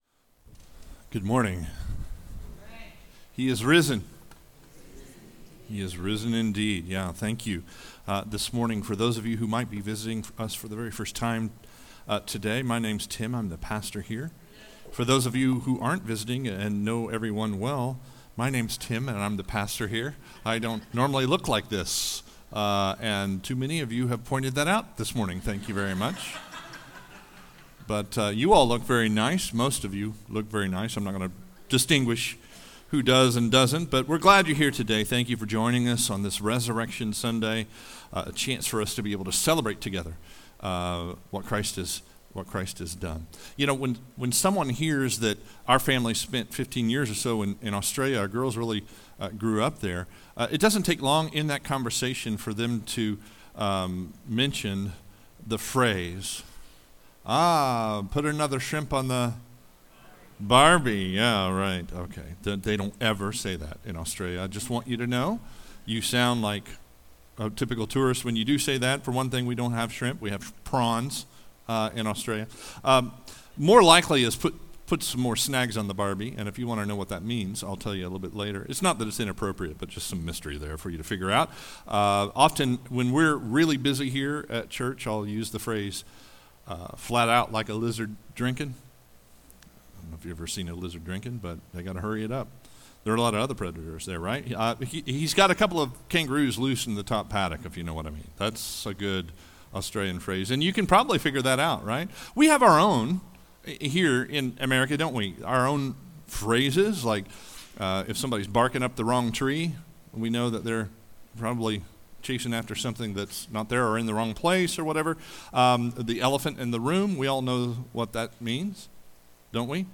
Sermons | Watermarke Church
Easter Sunday 2025